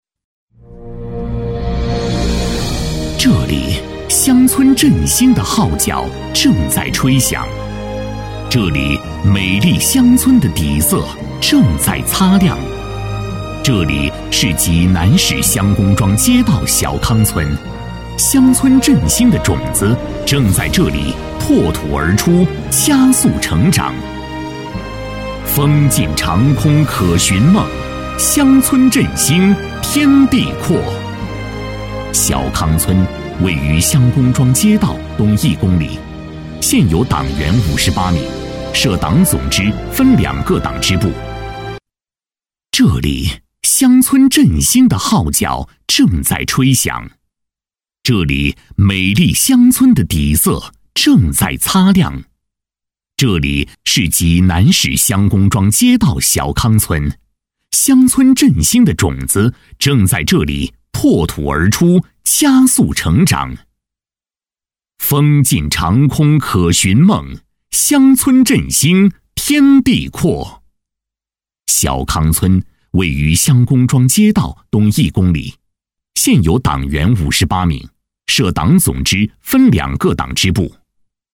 配音风格： 磁性，年轻
【专题】小康村乡村振兴